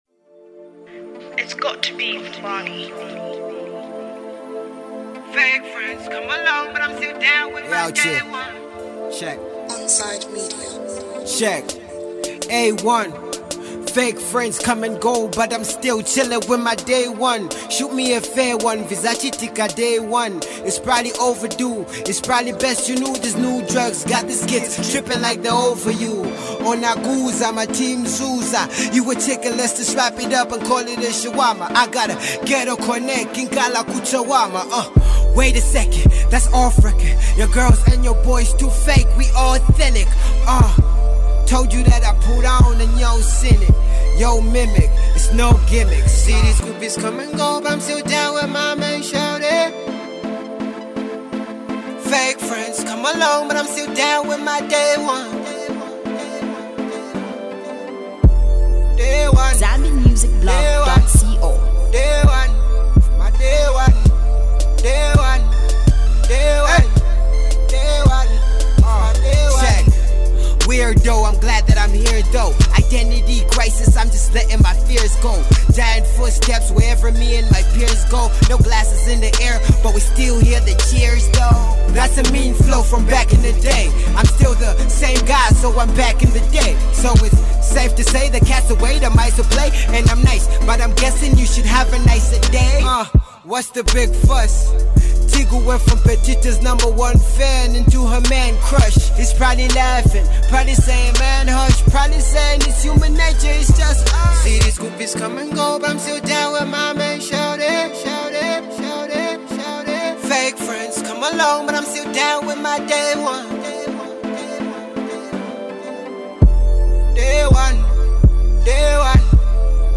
a Zambian Hip Hop music duo